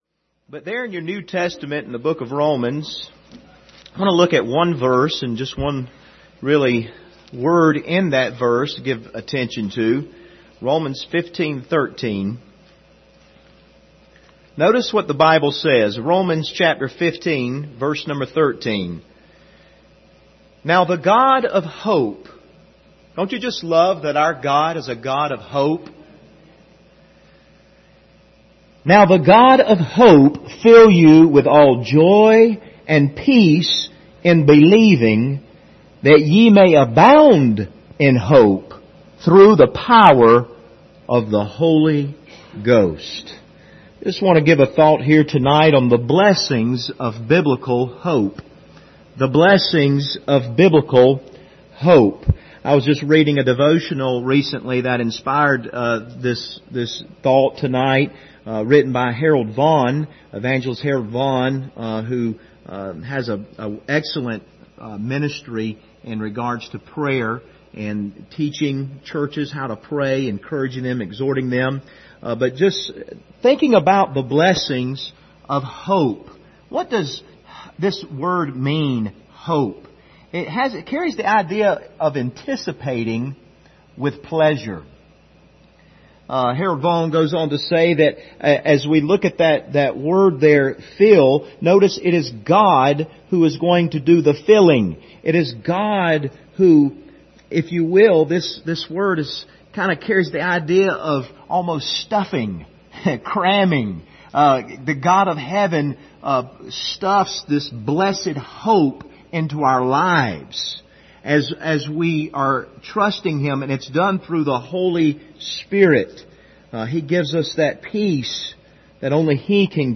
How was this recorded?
General Passage: Romans 15:13 Service Type: Wednesday Evening Topics